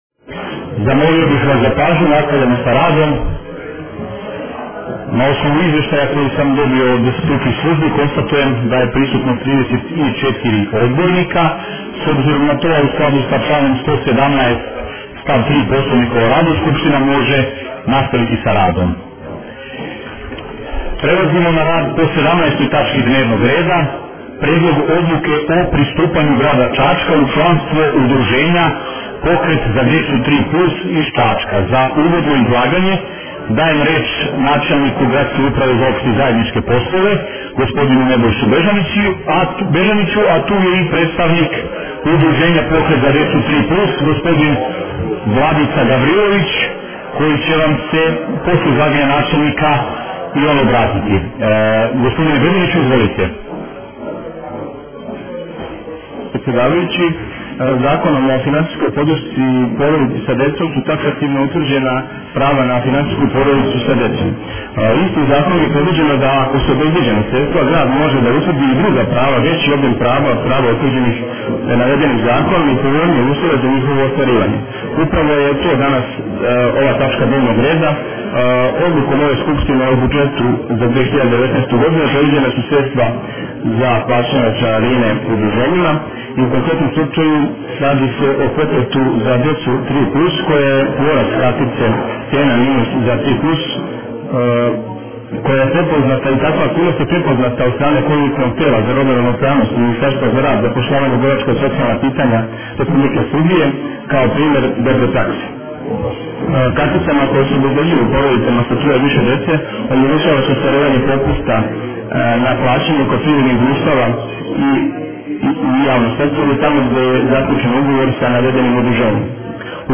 Двадесет седма седница Скупштине града - Е-ПАРЛАМЕНТ